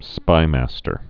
(spīmăstər)